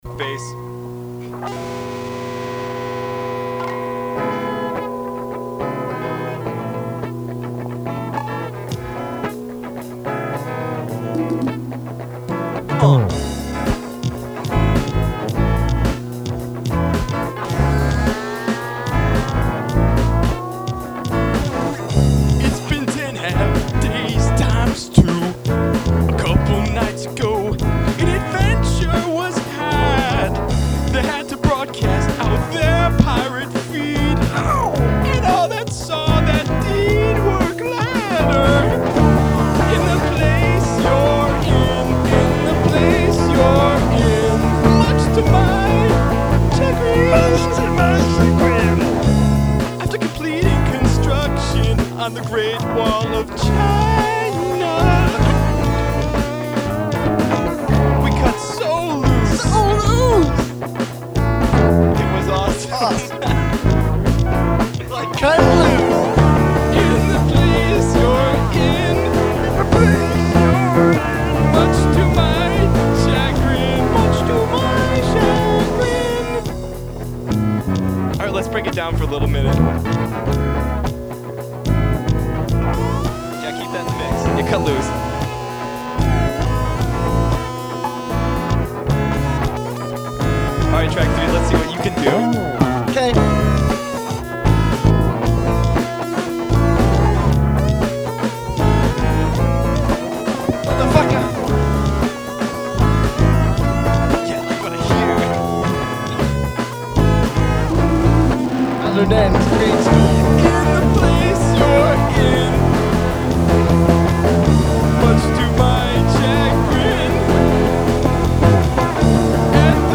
two-piece four-track project